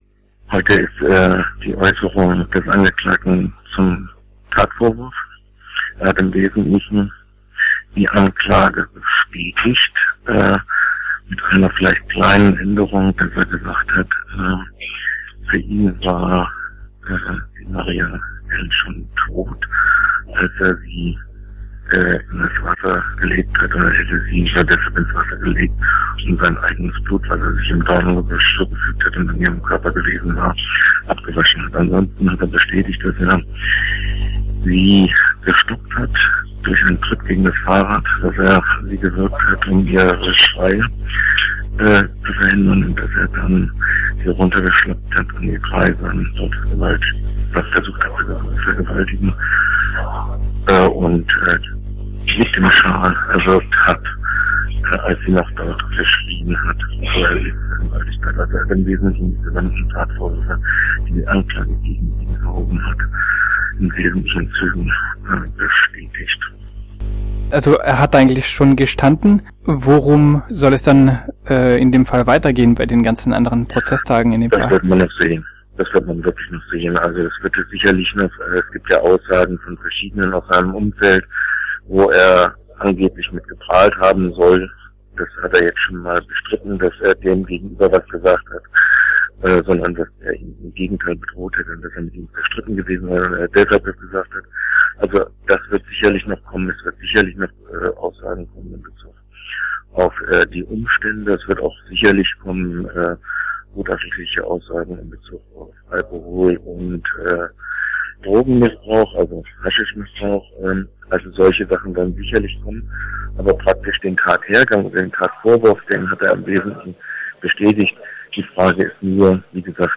Er hat ihn zunächst gefragt, worum es am heutigen Prozesstag konkret ging. Für die schlechte Qualität der Telefonverbindung bitten wir um Verzeihung.